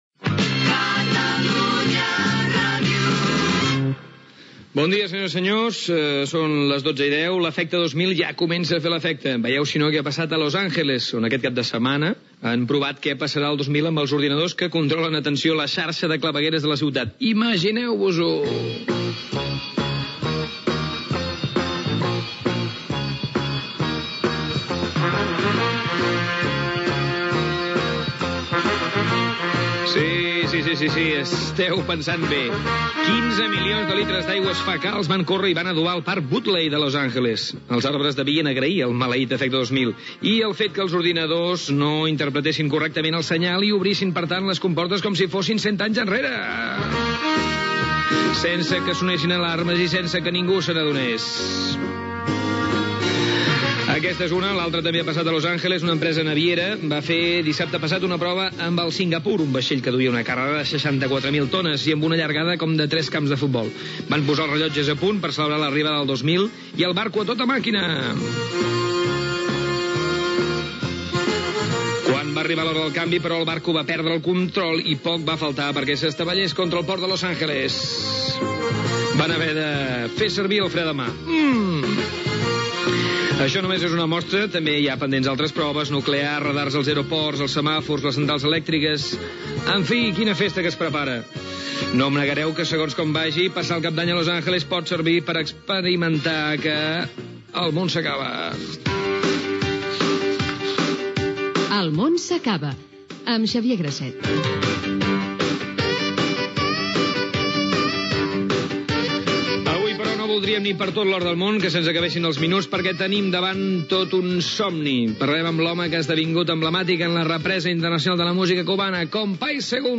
Indicatiu de l'emissora, prova a Los Angeles per comprovar les possibles conseqüències informàtiques de l'efecte 2000, careta del programa, sumari, entrevista al músic i compositor cubà Compay Segundo
Represa de l'entrevista a Compay Segundo sobre el disc "Calle salud" i la seva filosofia de vida Gènere radiofònic Entreteniment